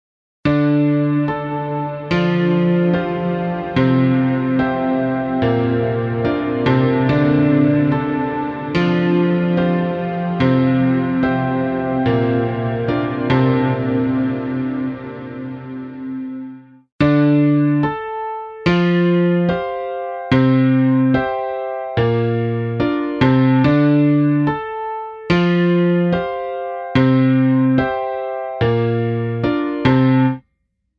UltraTap | Piano | Preset: WideVerb
Piano-WideVerb-Wet-Dry.mp3